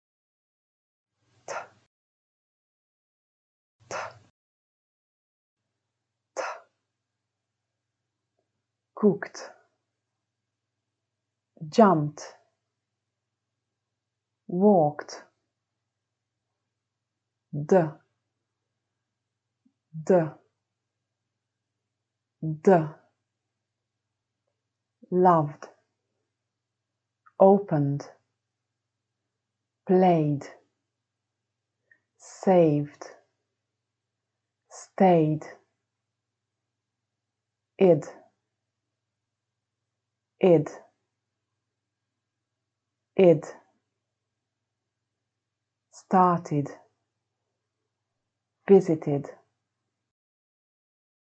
Do you hear /t/, /d/ or /ɪd/?